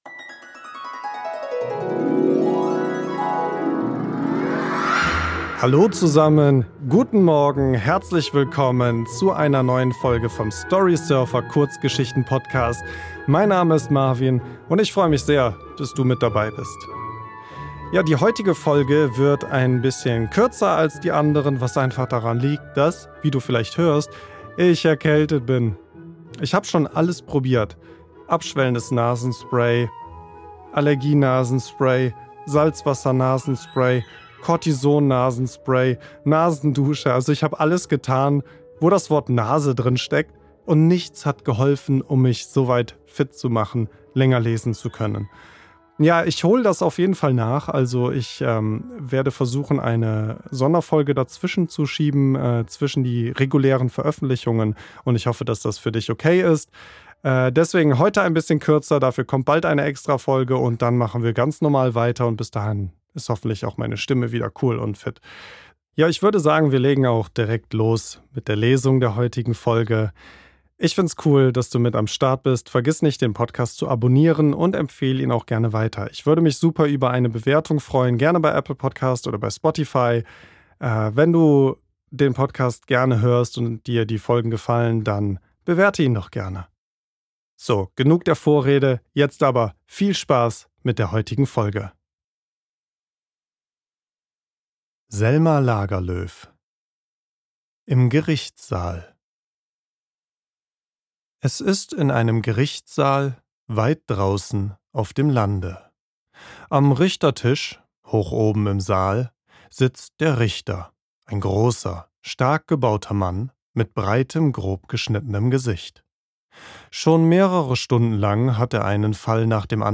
Selma Lagerlöf - Im Gerichtssaal | Gerichts- und Justiz-Geschichte | Storysurfer Podcast ~ Storysurfer - Der Kurzgeschichten Hörbuch Podcast